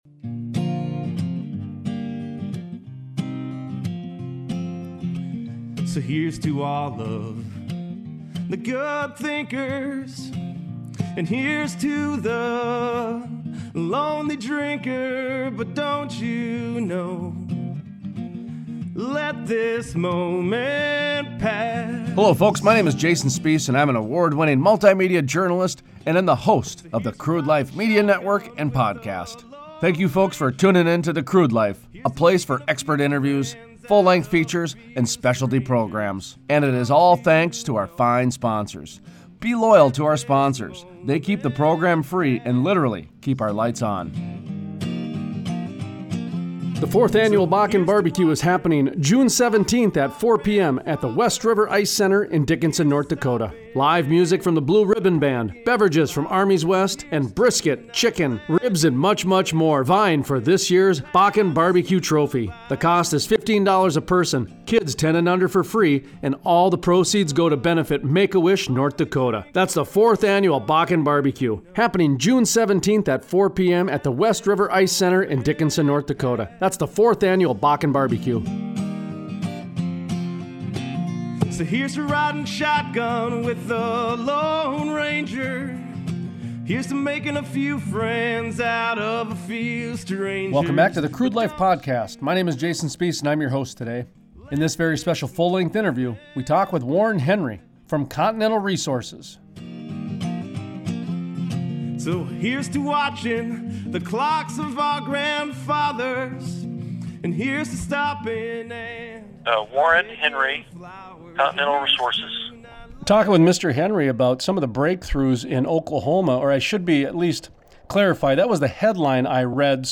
Other highlights from the interview: An overview of the counties in Oklahoma where rigs are added.